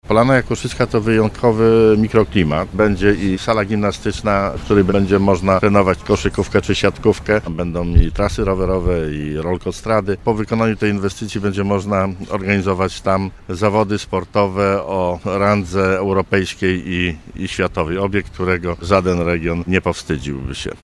O szczegółach inwestycji mówił Marszałek Województwa Dolnośląskiego, Cezary Przybylski,